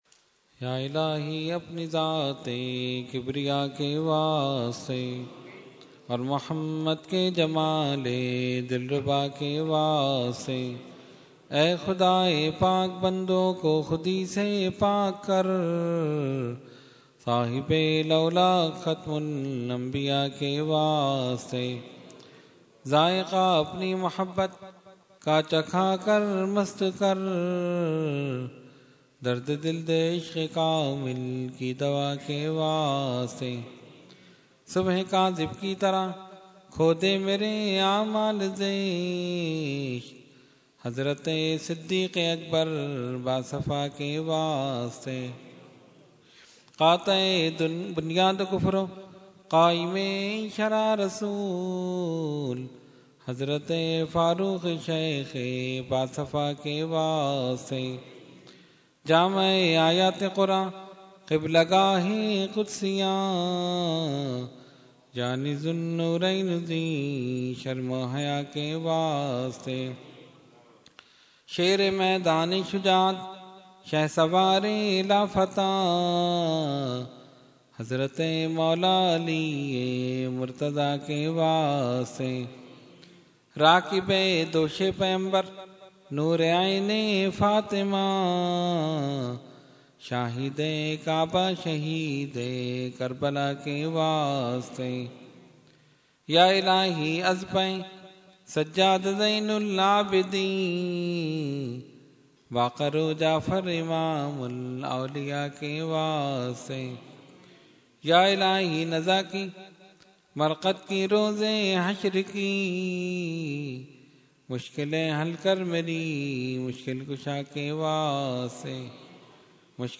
Dua – Subh e Baharan 2014 – Dargah Alia Ashrafia Karachi Pakistan